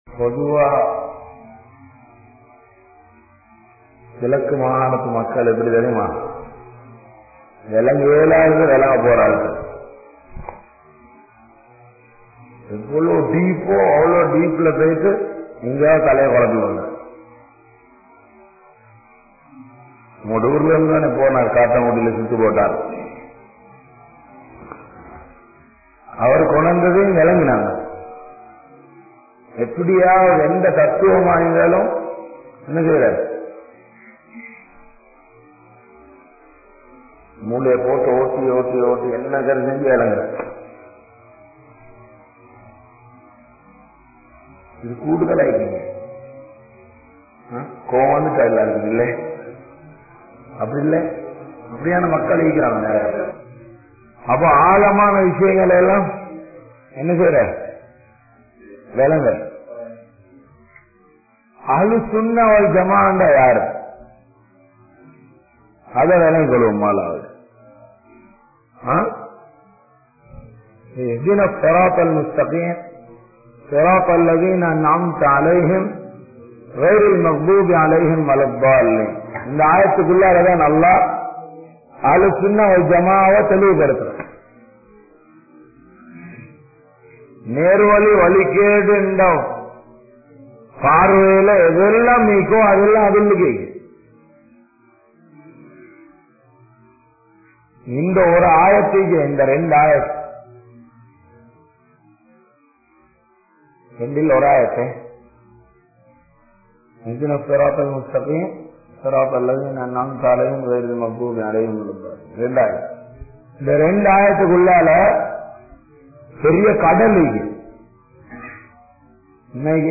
Nerana Vali Ethu?(நேரான வழி எது?) | Audio Bayans | All Ceylon Muslim Youth Community | Addalaichenai